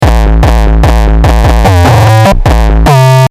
Melodische Kicks mit Logic selber bauen
Hab mal nur mit den Logic Internen sachen versucht was hinzubiegen, da geht bestimmt noch mehr Projekt im anhang...